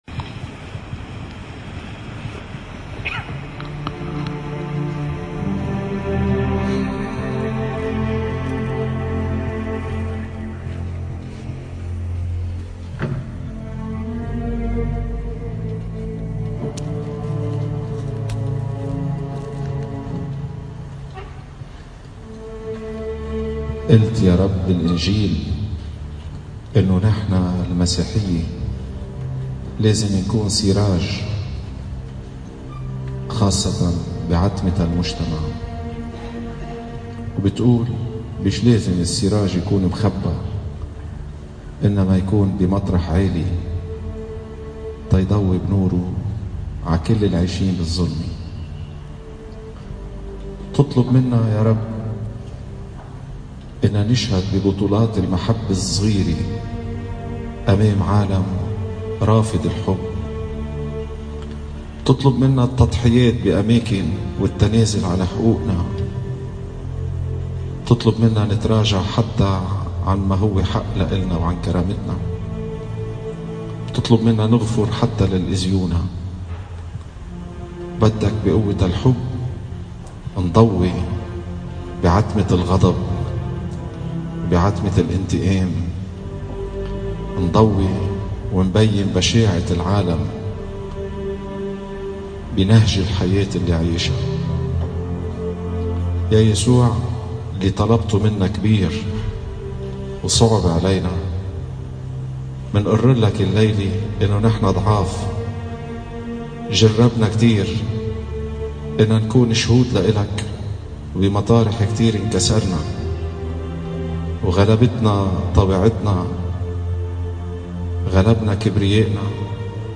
سجود أمام القربان المقدس